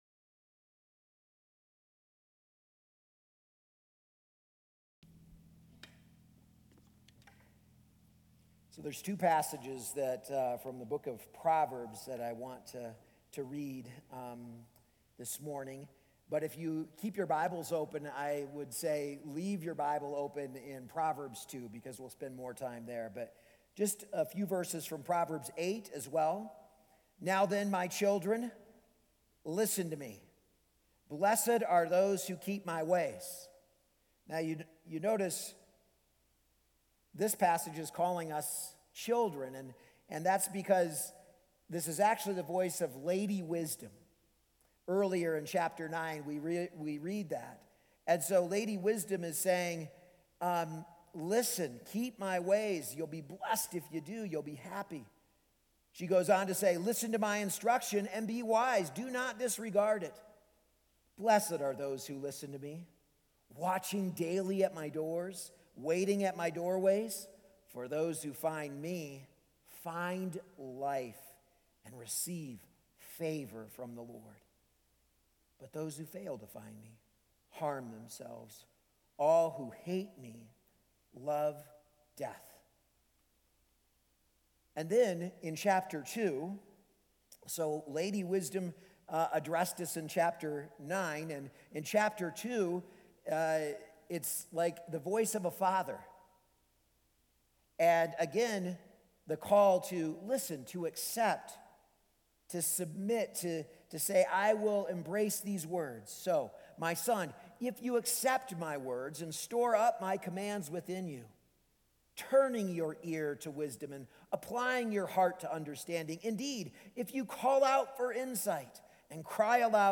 A message from the series "Daring to Draw Near."